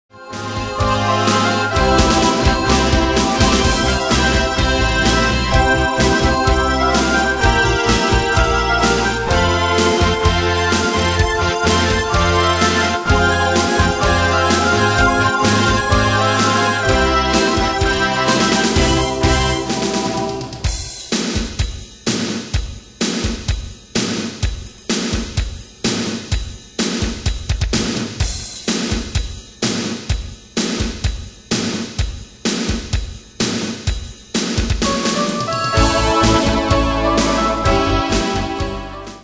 Demos Midi Audio